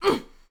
SFX_Battle_Vesna_Defense_05.wav